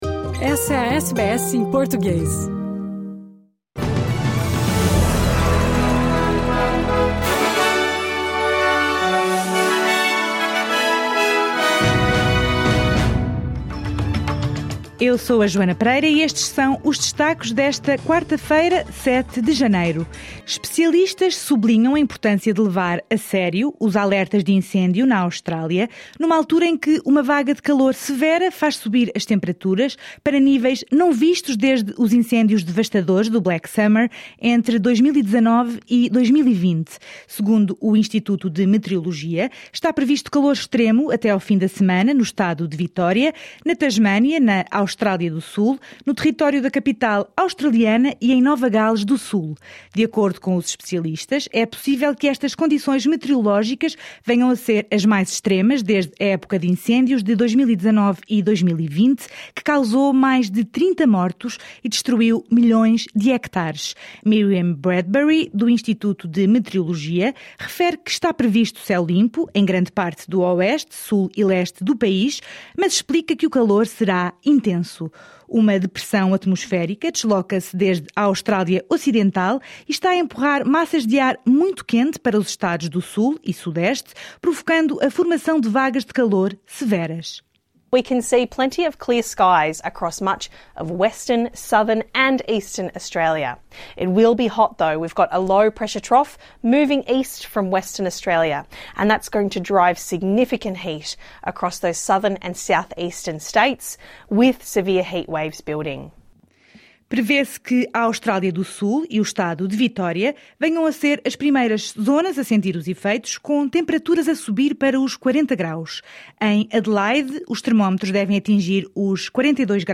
Estas e outras notícias em destaque no noticiário de hoje.